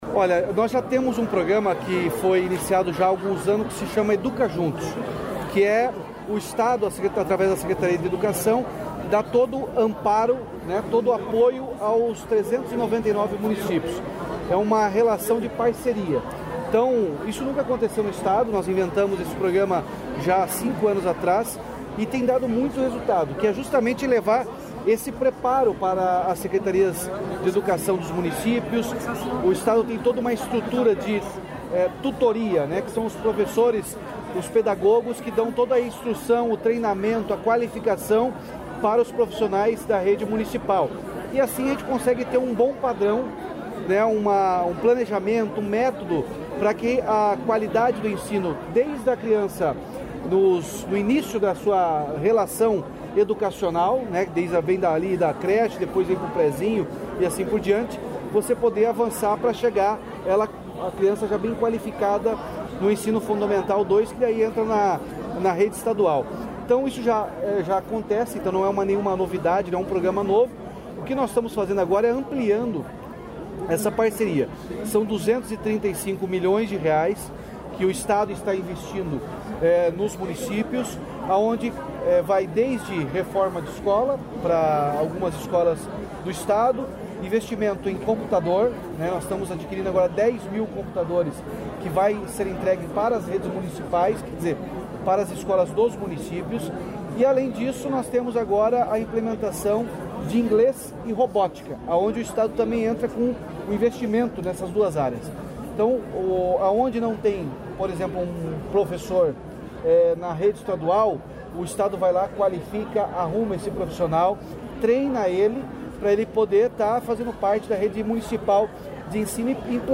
Sonora do governador Ratinho Junior sobre implementação de inglês e robótica